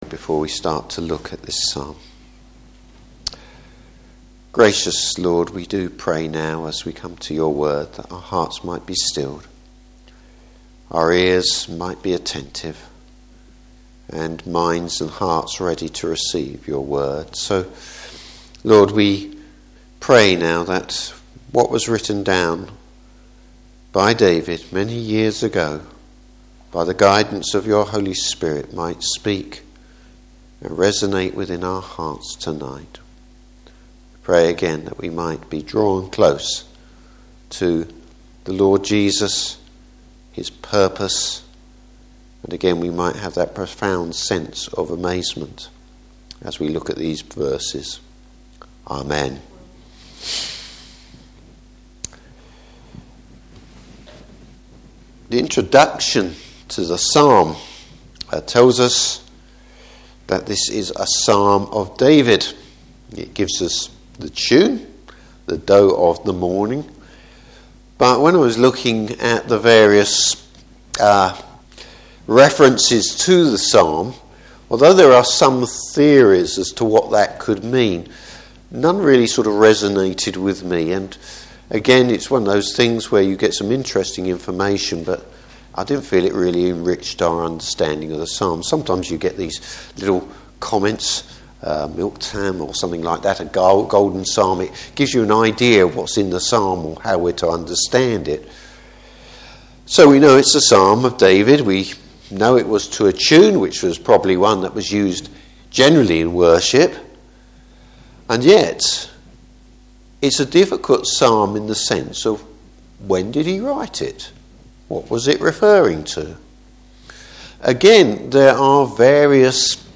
Passage: Psalm 22:1-21. Service Type: Evening Service The Psalm of the Cross!